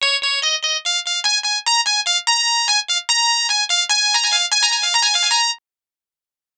Twisting 2Nite 3 Clav-C.wav